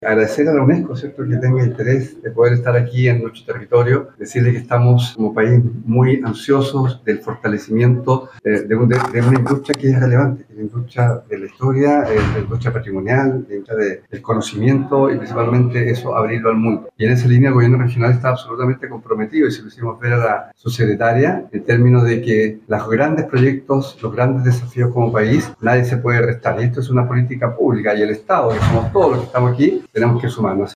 El gobernador Regional, Alejandro Santana, resaltó esta visita y aseguró que el Gobierno Regional está comprometido con la protección y mantención del patrimonio cultural.